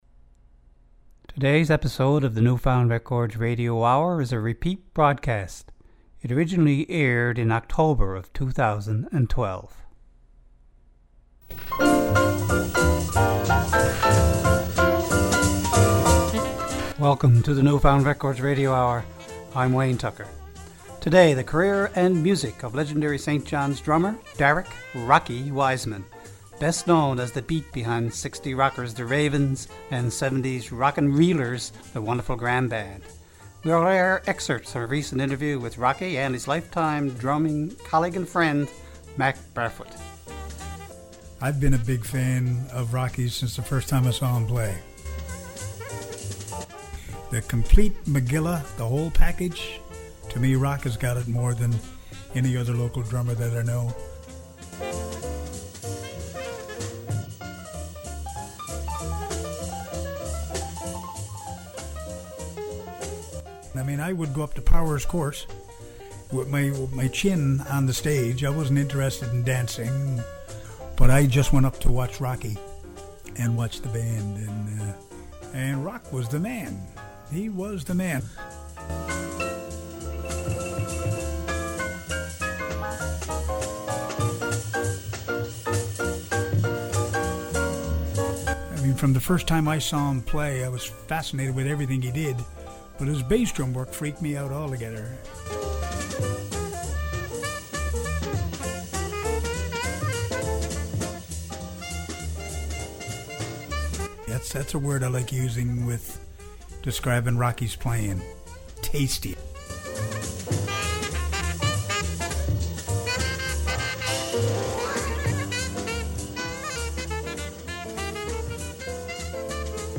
Vinyl records by Newfoundland & Labrador's pioneer recording artists.
Edited re-broadcast of show which originally aired in October, 2012.
Recorded at CHMR, MUN, St. John's, NL.